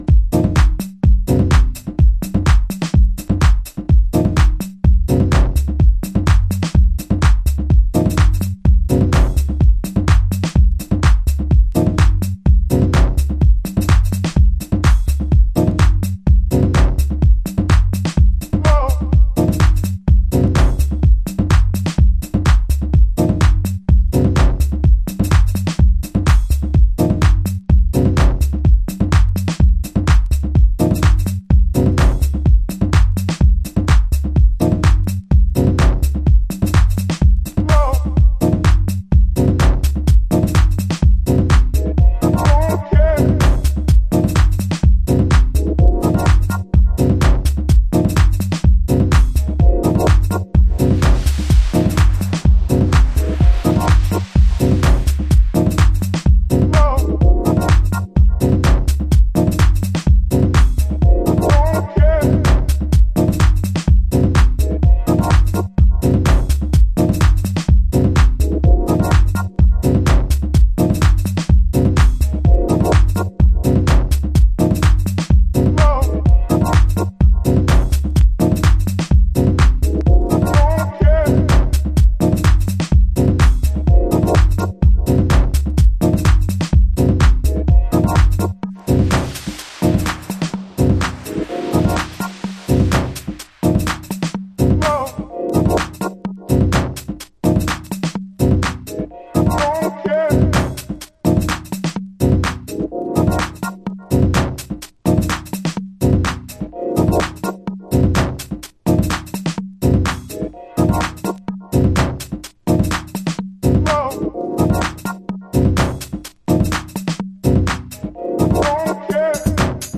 重心低めのモダンハウス。ヴォイスサンプルで徐々に空間が熱を帯びていきます。